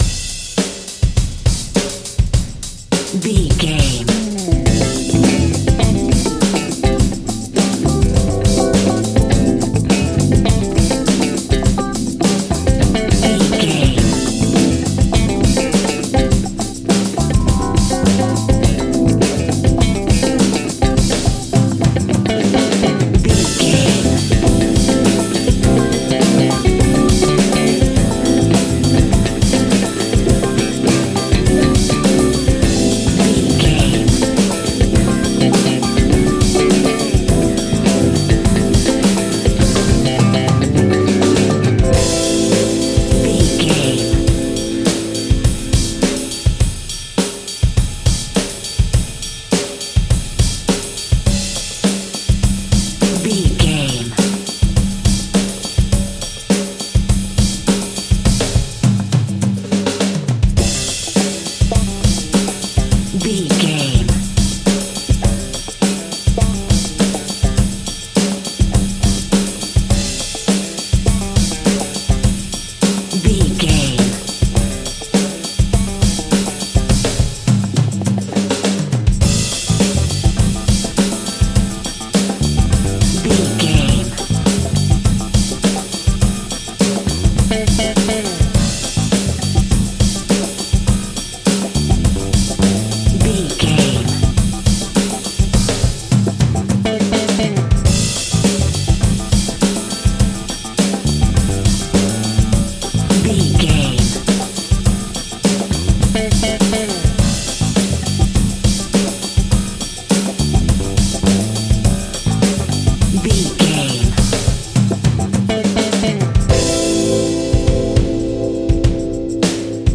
Aeolian/Minor
groovy
lively
electric guitar
electric organ
drums
bass guitar
saxophone
percussion